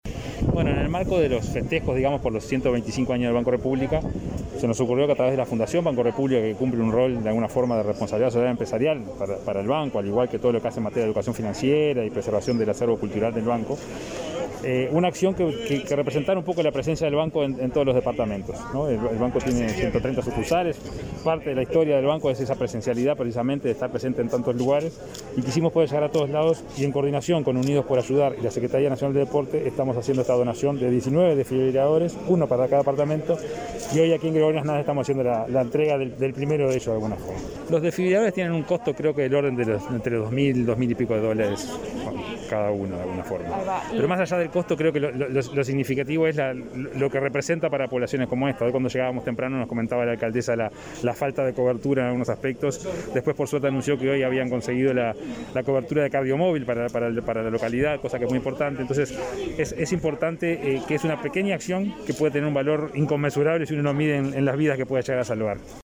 Declaraciones del presidente del BROU, Salvador Ferrer